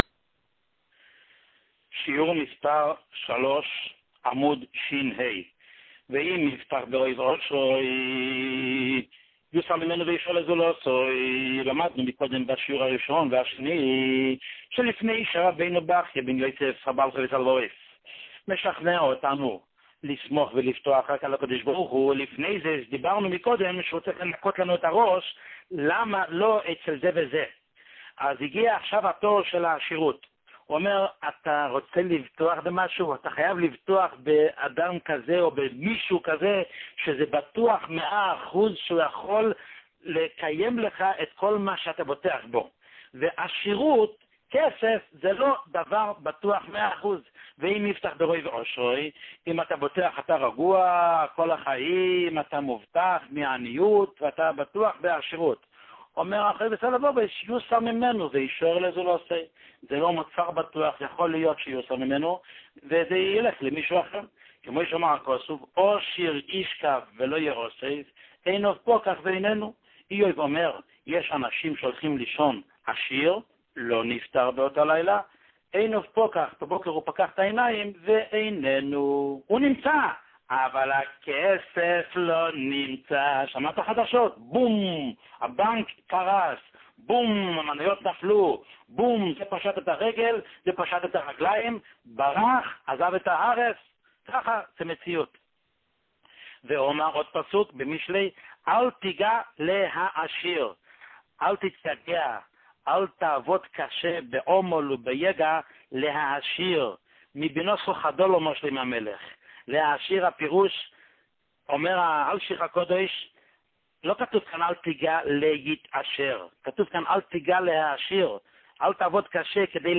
שיעור מספר 3